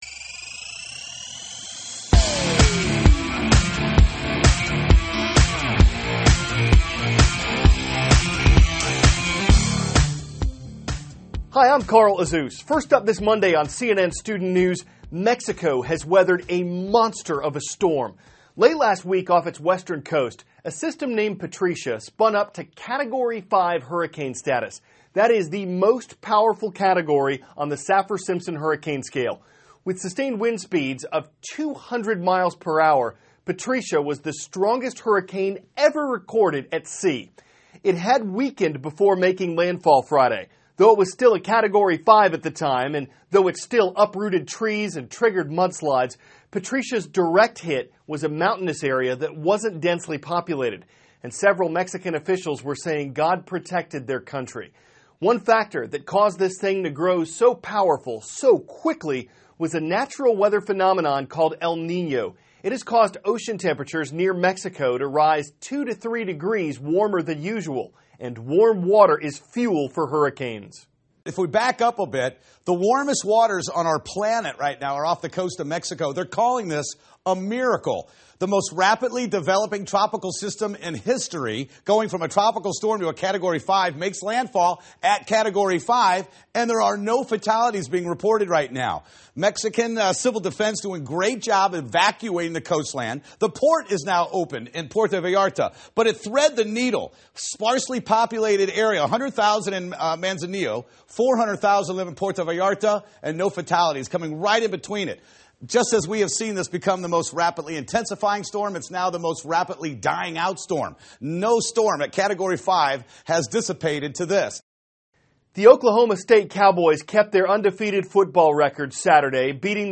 (cnn Student News) -- October 26, 2014 Hurricane Patricia Makes Landfall in Mexico; Buildings Turn Blue to Mark U.N. Day; NASA Moves Forward with Mars Rocket Program THIS IS A RUSH TRANSCRIPT.